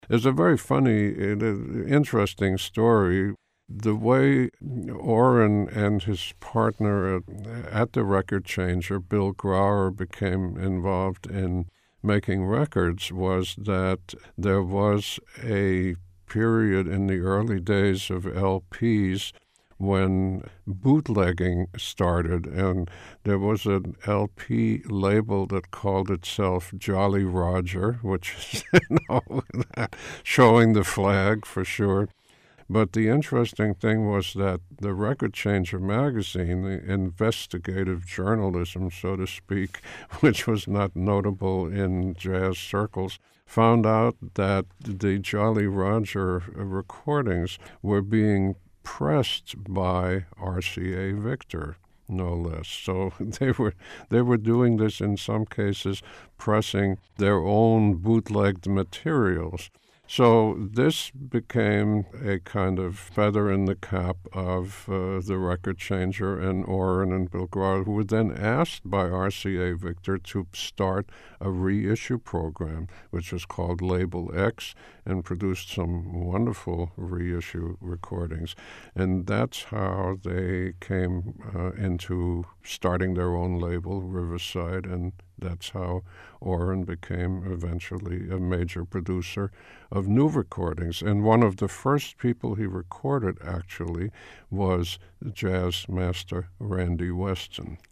Transcript of conversation with Dan Morgenstern